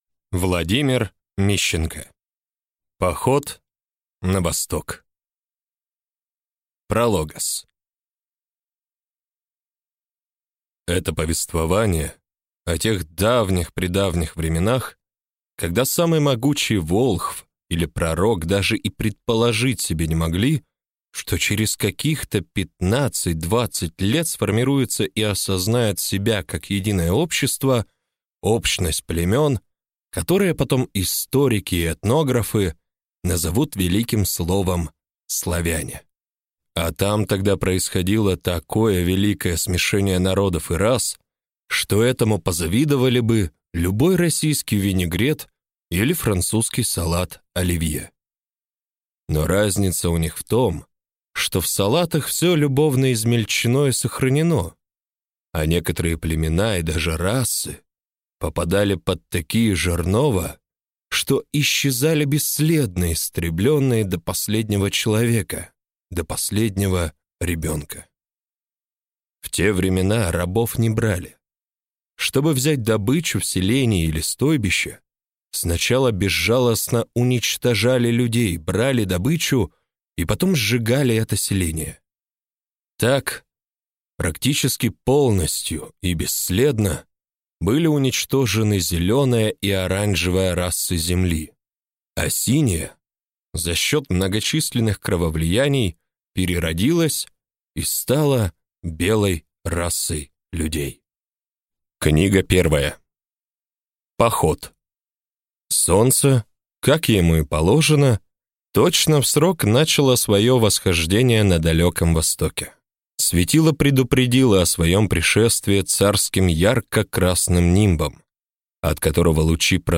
Аудиокнига Поход на Восток | Библиотека аудиокниг